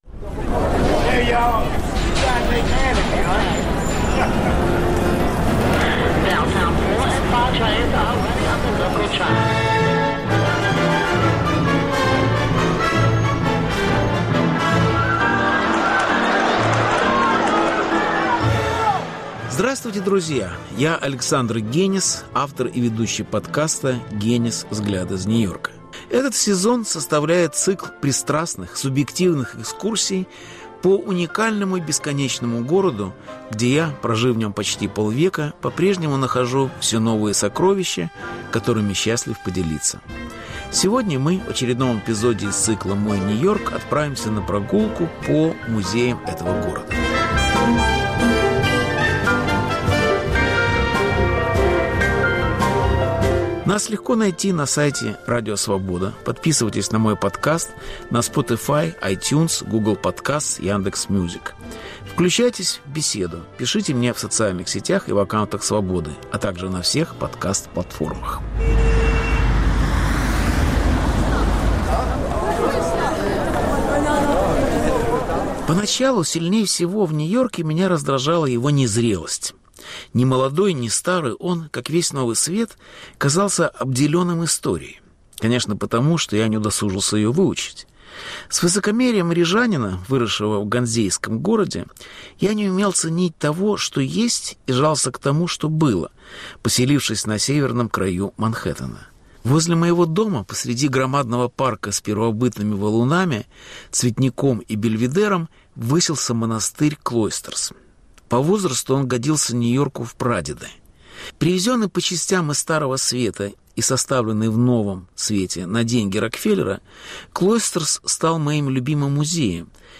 Повтор эфира от 16 апреля 2023 года.